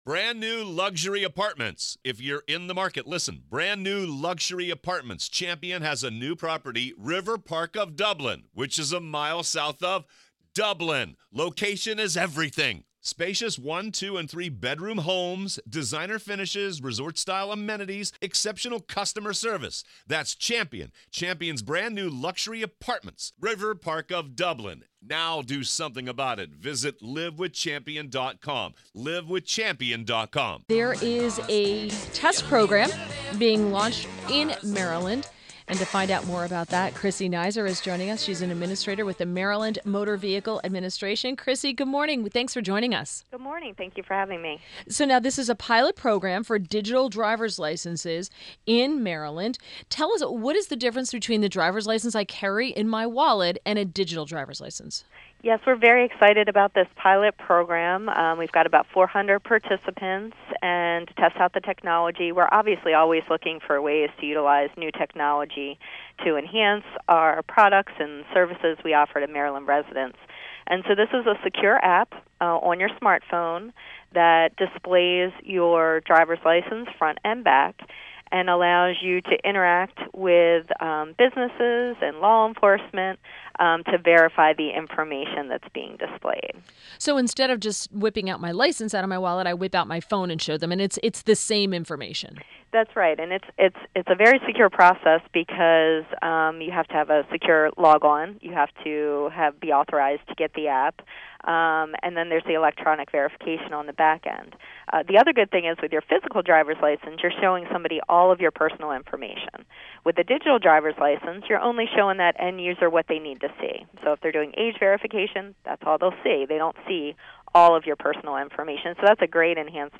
WMAL Interview - CHRISSY NIZER 07.24.17